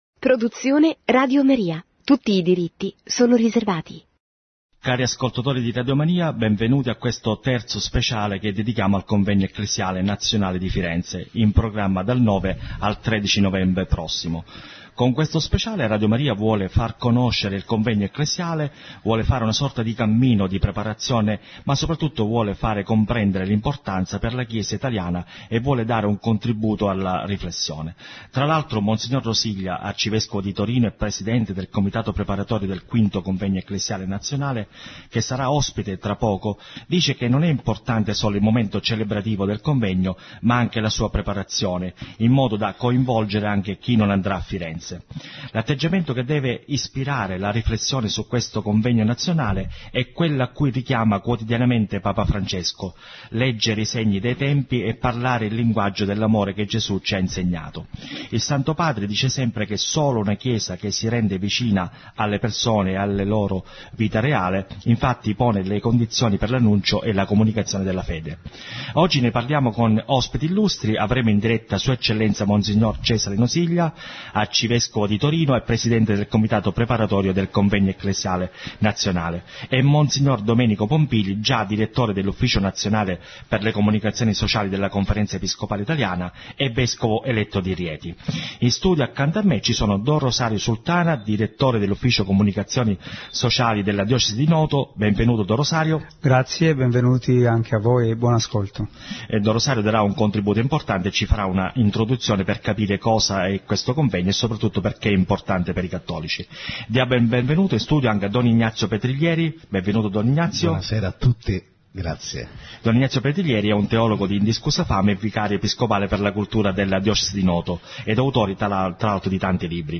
Grandissimo successo in termini di ascolto e di interesse per il terzo programma speciale sul Convegno Ecclesiale di Firenze, che Radio Maria ha trasmesso in diretta martedì 30 giugno alle ore 18,00.